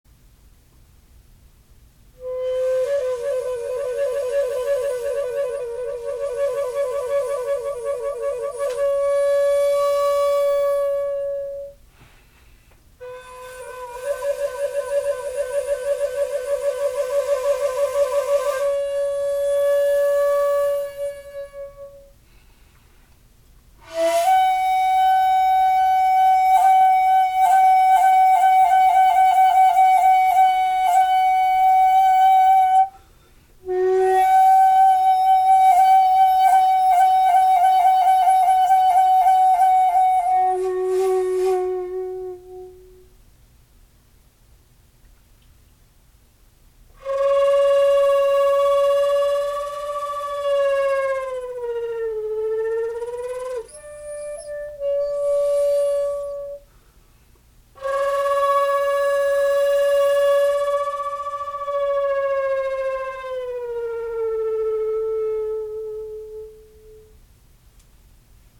録音はしていますが、所詮機械ですので生の音にはほど遠いことをご留意ください。
c.特殊音符－＞コロなどは、江戸時代から本来こんな感じの音だったと思わせる自然な音です。
玉音(たまね)なども変なハレーション(びびり)を起こさず自然に聞こえます。
地無し愛好家にとってはたまらない良い響きです。